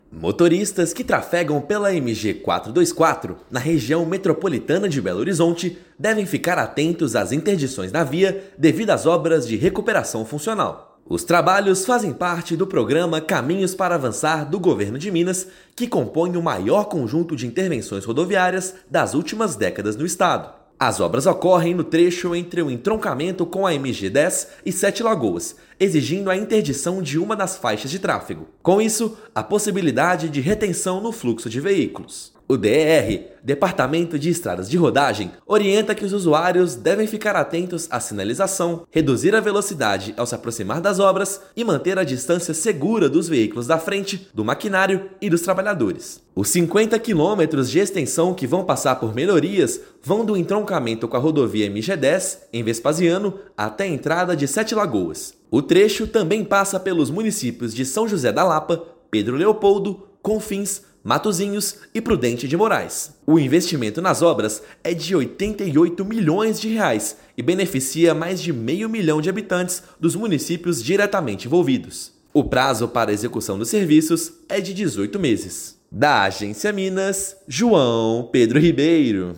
[RÁDIO] Obras na MG-424 exigem atenção redobrada de motoristas nos próximos meses
Trecho entre a MG-010 e Sete Lagoas terá interdições para recuperação da rodovia. Ouça matéria de rádio.